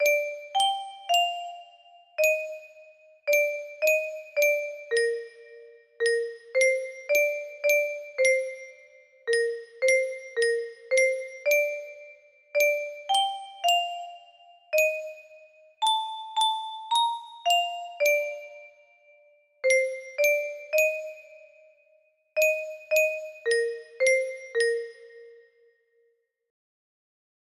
011 music box melody
Full range 60